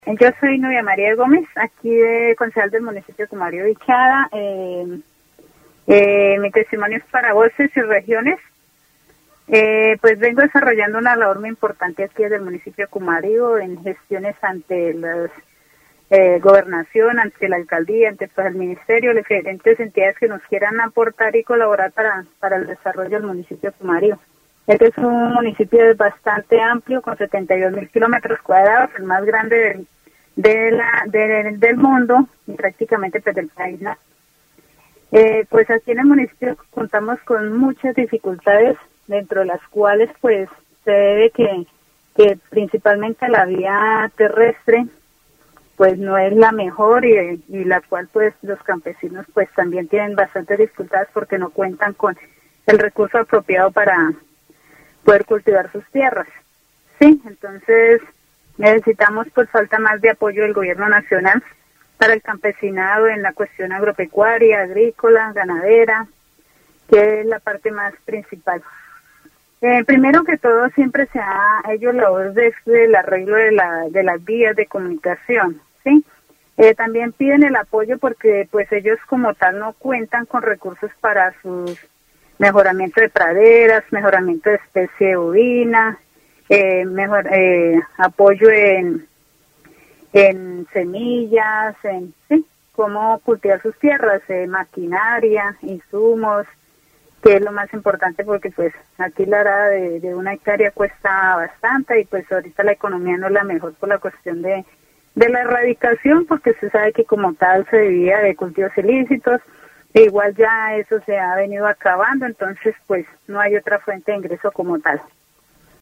Interview with Nubia María Gómez, councilwoman of Cumaribo, Vichada, about the challenges faced by farmers in her municipality, including the need for government support in the agricultural, livestock, and agribusiness sectors.
Vichada (Región, Colombia) -- Grabaciones sonoras , Programas de radio , Gómez Peña, Nubia María -- Entrevistas , Dificultades campesinas